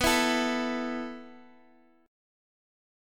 A5/B chord
A-5th-B-x,x,x,4,5,5.m4a